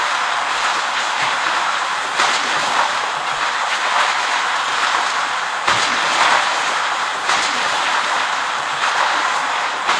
Звук вьюги и шаги на снегу:
snowstorm4.wav